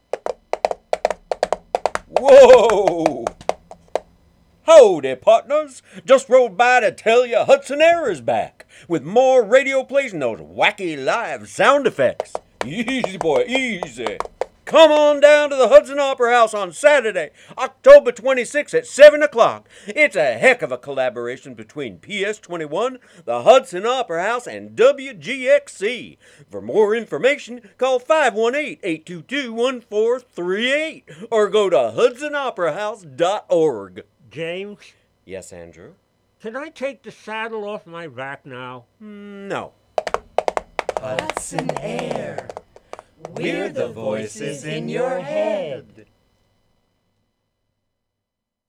Wave Farm | Cowboy PSA for Hudson Air's October 26 performance at the Hudson Opera House, which is co-sponsored by PS21, WGXC, and the Hudson Opera House.
HudsonAir_CowboysPSA.wav